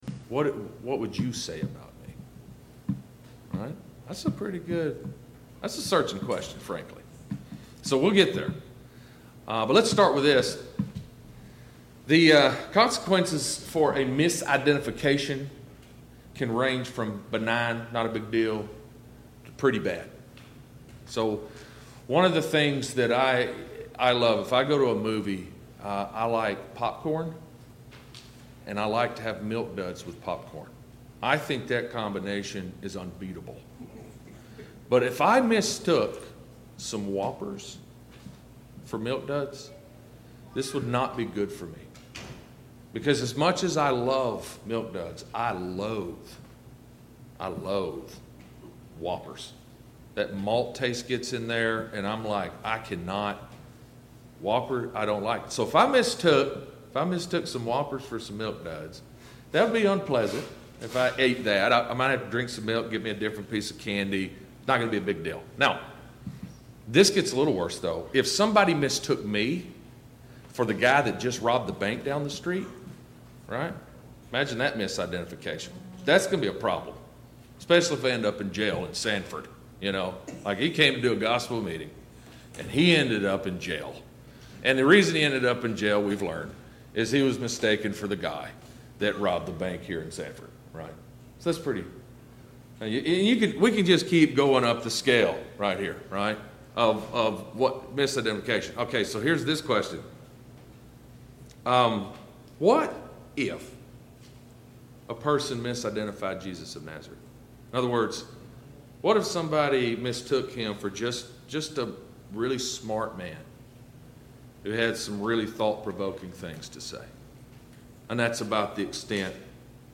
Service Type: Gospel Meeting Download Files Notes Topics: Jesus Christ « 9.